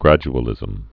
(grăj-ə-lĭzəm)